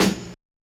SNARE GRITTY 1.wav